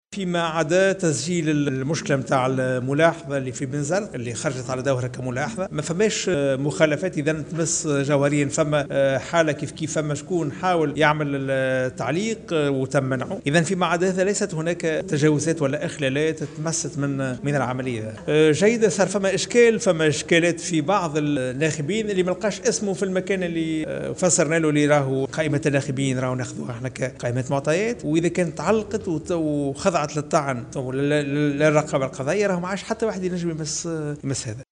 أكد رئيس الهيئة العليا المستقلة للإنتخابات شفيق صرصار في تصريح لمراسل الجوهرة "اف ام" اليوم أن عملية الإقتراع لم تشهد إخلالات و لا تجاوزات تمس جوهر الإنتخابات.